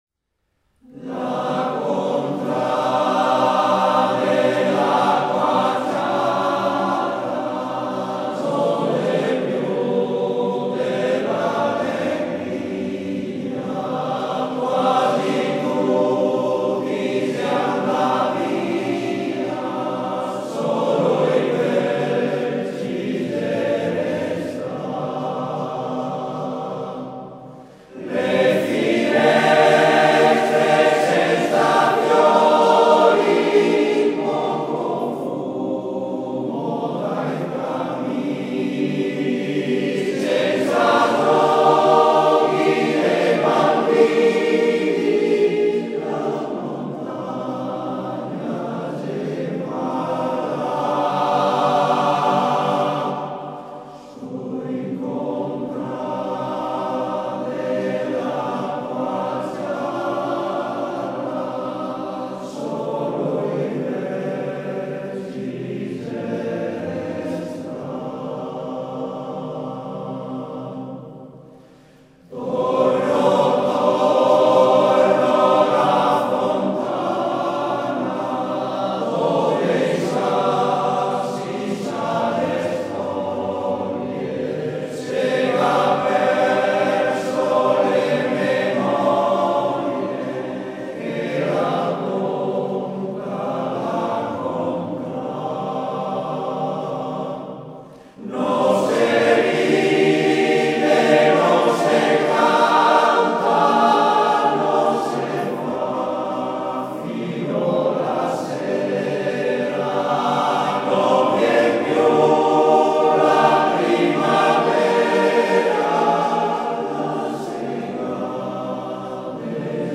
Coro LA GERLA di Spinea